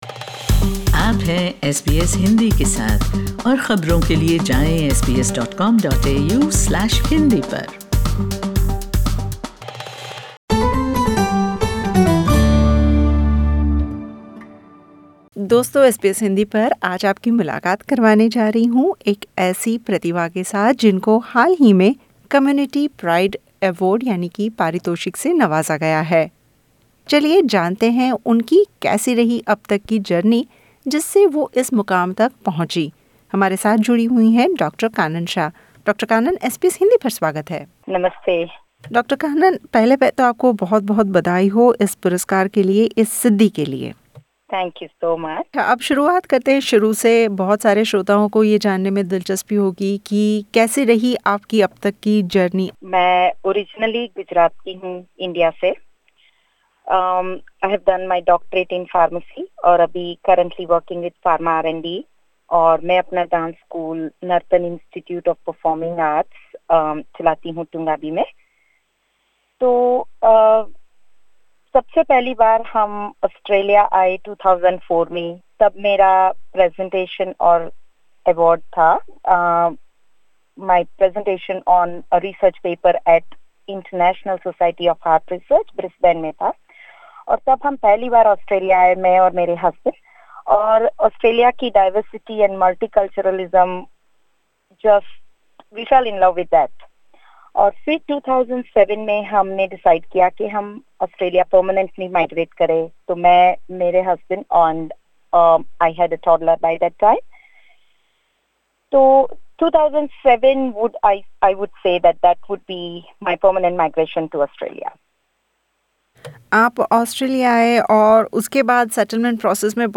इस बी इस हिंदी के साथ की बातचीत में उन्होंने बताया के - आपने काम के प्रति अपना समर्पण और पेशन बरकरार रखना चाहिए बाकी इनकी ऑस्ट्रेलिया आने और इस मुकाम तक पहुंचने की यात्रा आम इंसान जैसी ही रही.